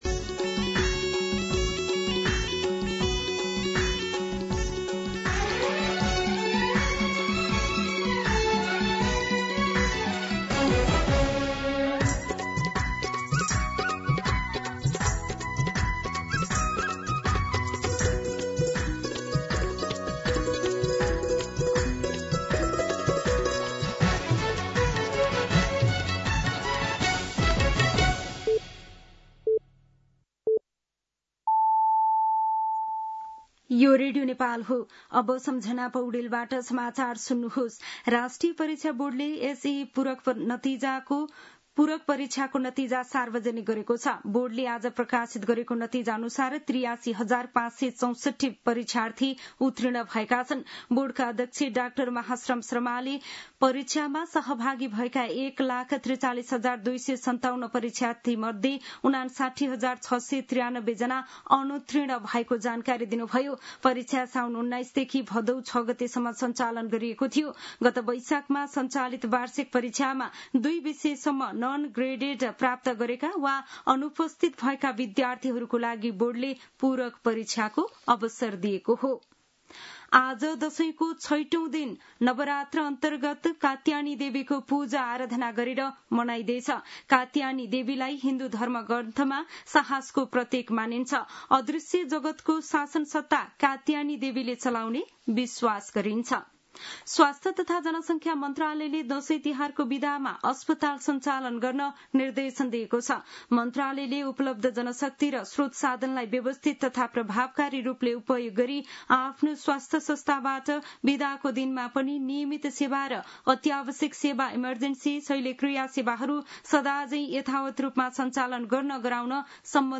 दिउँसो १ बजेको नेपाली समाचार : १८ पुष , २०२६
1-pm-Nepali-News-4.mp3